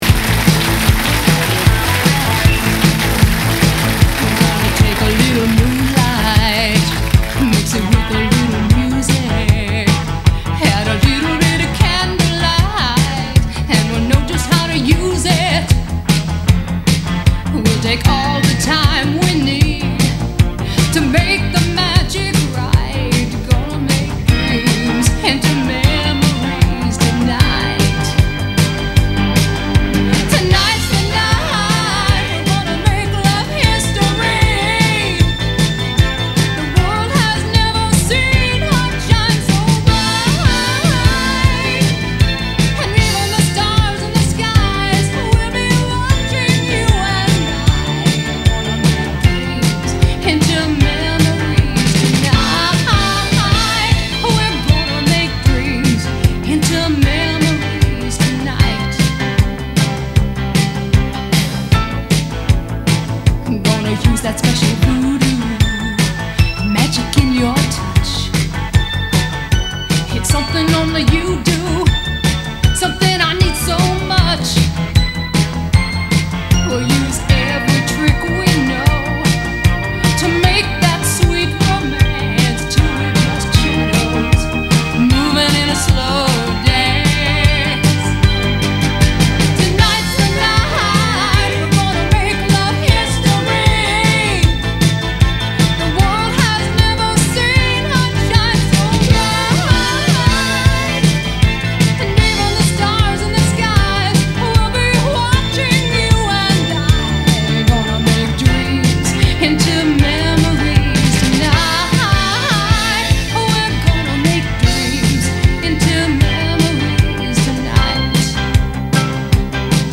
вокал